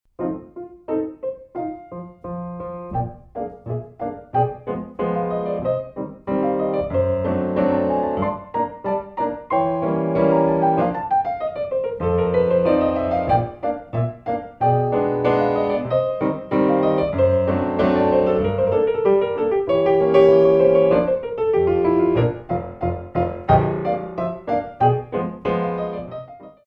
Relevés sur Pointes